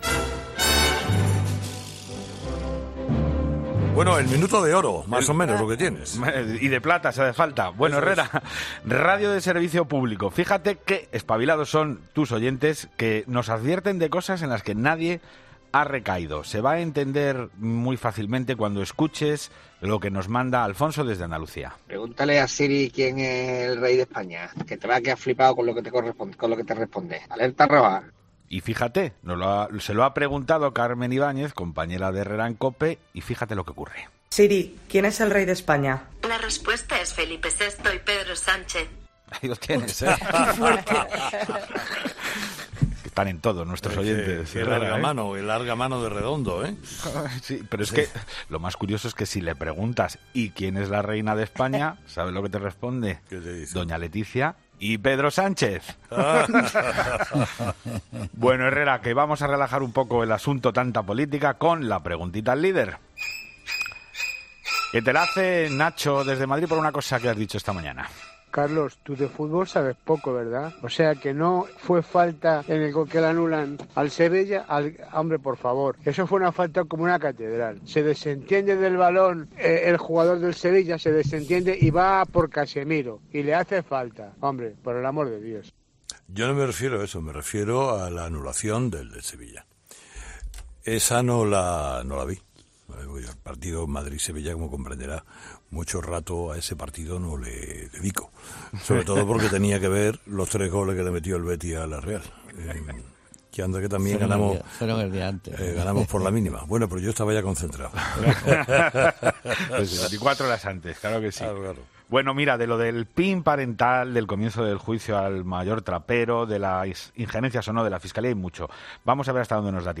Los oyentes de "Herrera en COPE" se quedan boquiabiertos cuando el asistente de voz recibe esta pregunta